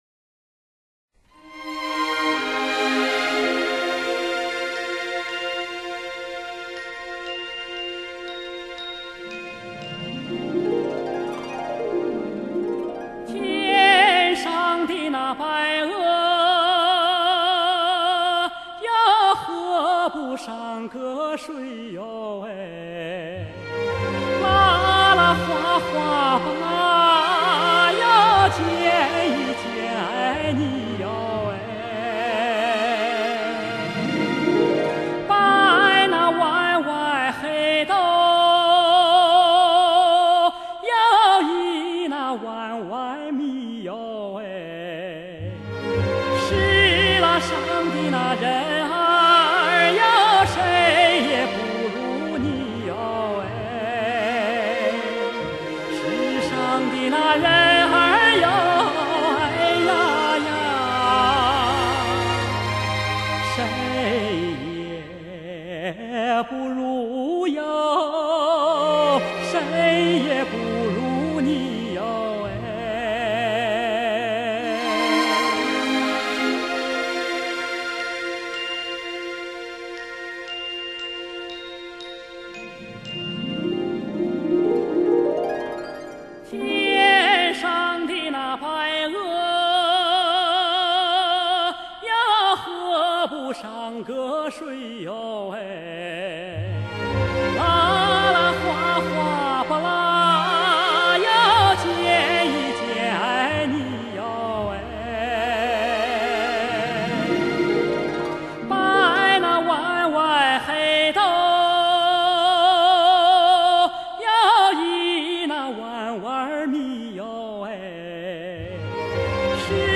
民樂演唱